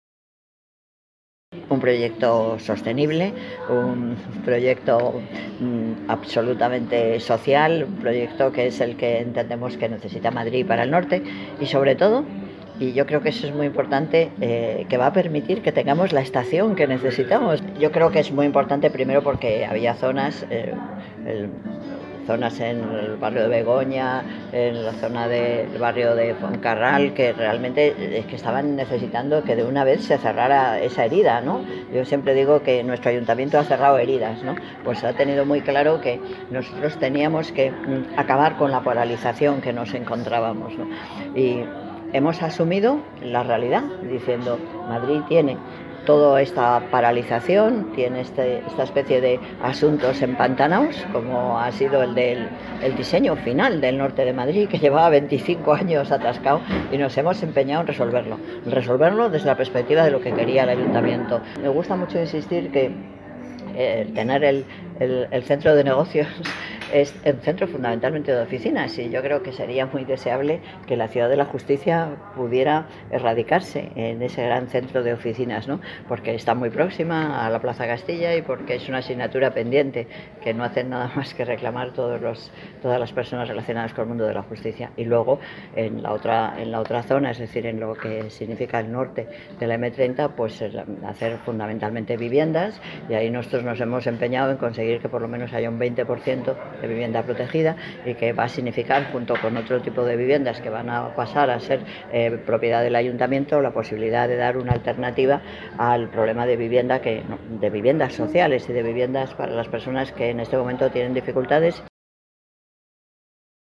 Nueva ventana:Declaraciones José Manuel Calvo, delegado Desarrollo Urbano Sostenible
Manuela Carmena, alcaldesa de Madrid